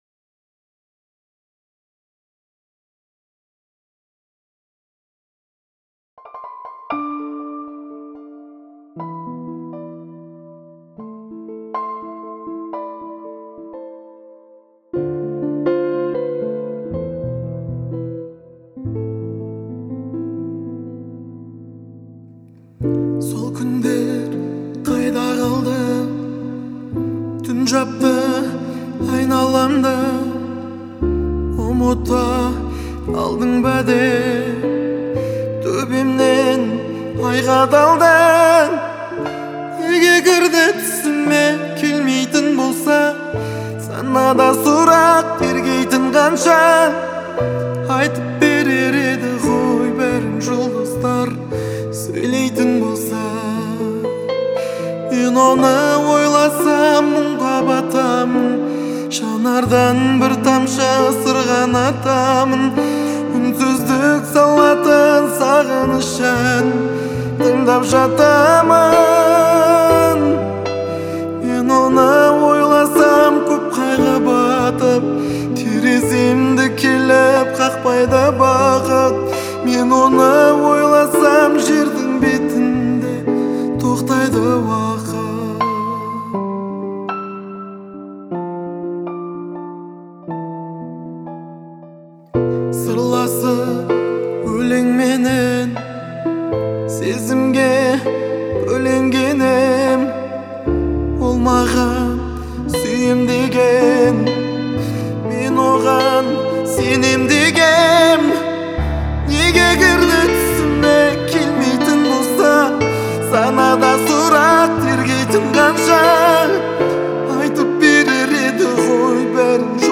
инструментальная композиция
выполненная в жанре поп.